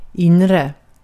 Uttal